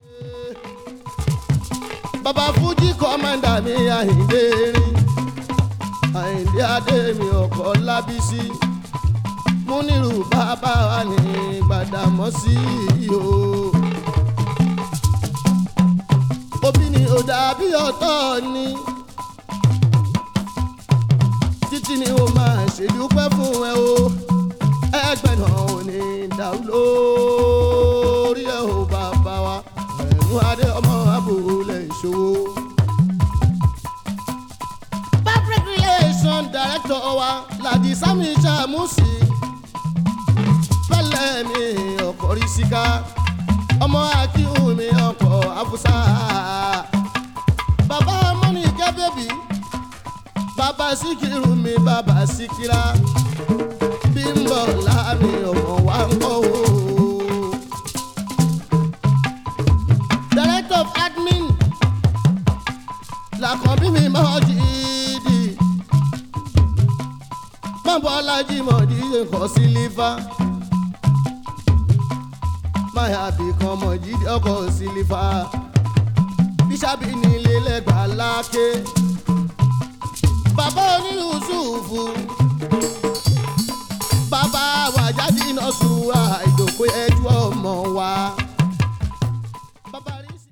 Sunny Adeと同様に楽曲はすべてノンストップで進行するので、非常に聴き応えがあります。